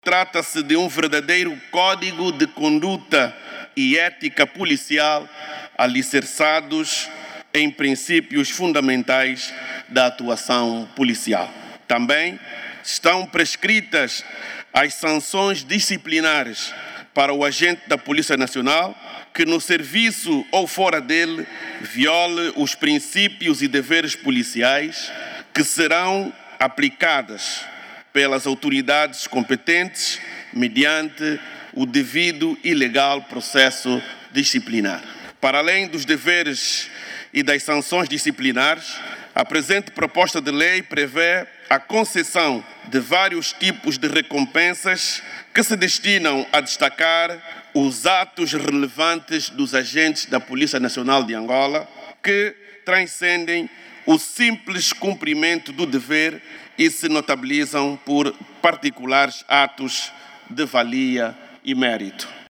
Durante a apresentação da proposta, o ministro do Interior, Manuel Homem, afirmou que o documento pretende estabelecer sanções mais rigorosas para comportamentos que contrariem os princípios éticos e deontológicos da Polícia Nacional.